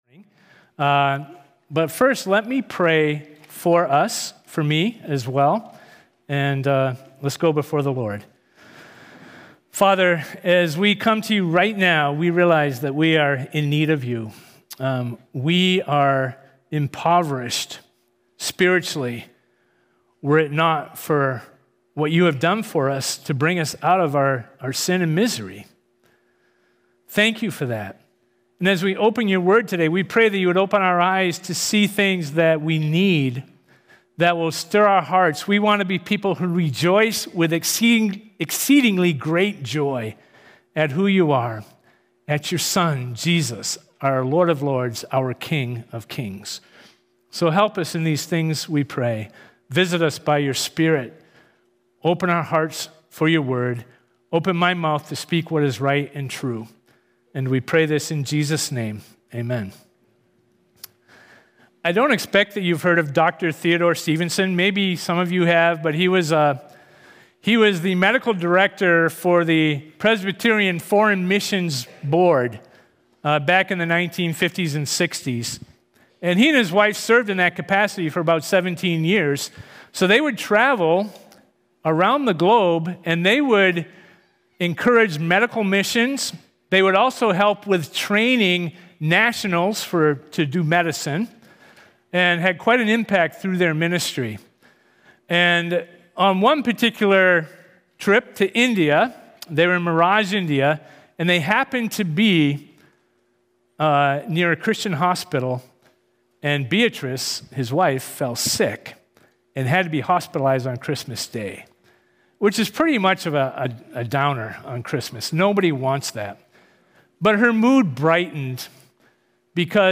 1 Sunday Sermon 12-29-24